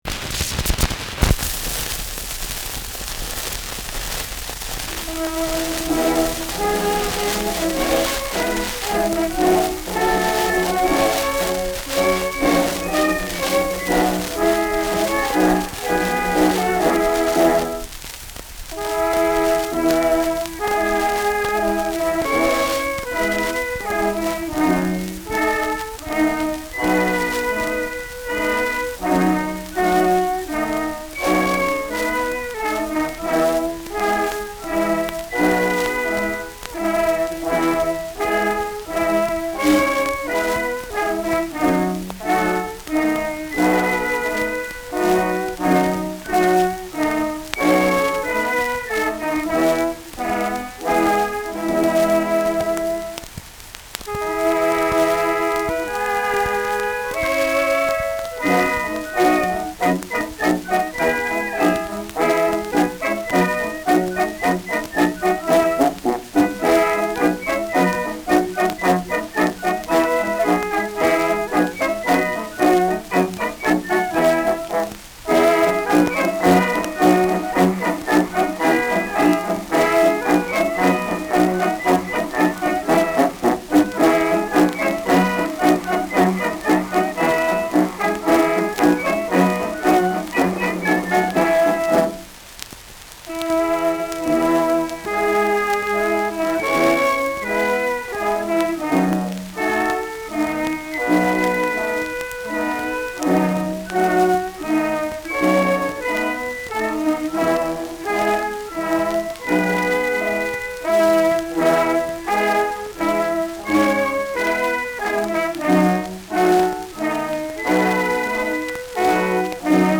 Schellackplatte
Stark abgespielt : Anfang stärker verrauscht : Vereinzelt kratzende Störgeräusche : Nadelgeräusch zu Beginn des zweiten Drittels : Vereinzelt leichtes Knacken : Starkes Störgeräusch im letzten Drittel
Stadtkapelle Fürth (Interpretation)
[Berlin] (Aufnahmeort)
Schützenkapelle* FVS-00006